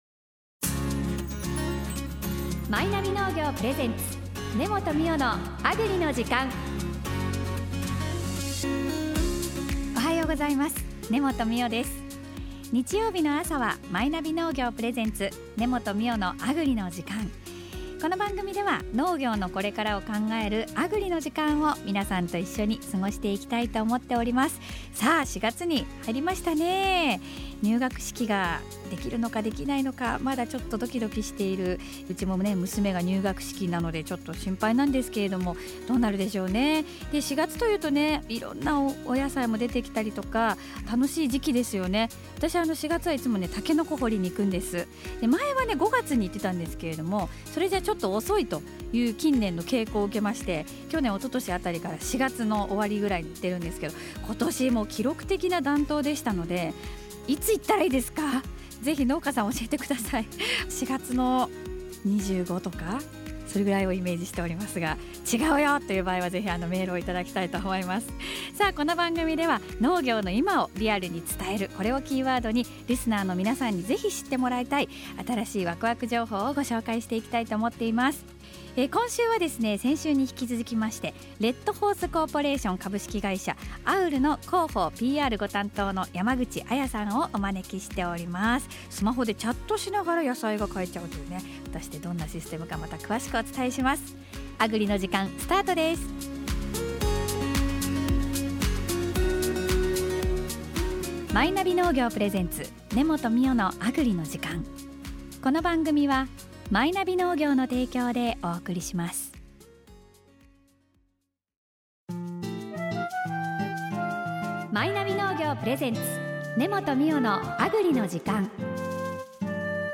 フリーアナウンサーで、気象予報士でもある、根本美緒さんが、様々な分野で「農業の今」を支えている方々をゲストにお迎えし、「農業のこれから」を考える15分間の